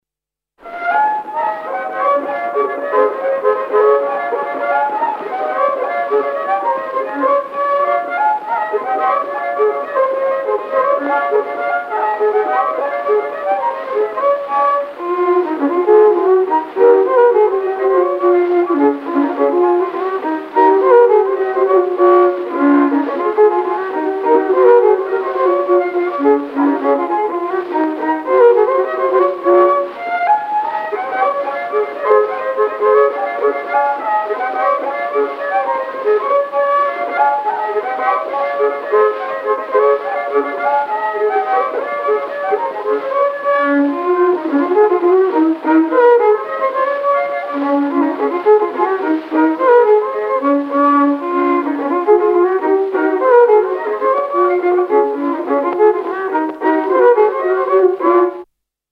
I viiul
II viiul
28 Labajalg Unt aea taga.mp3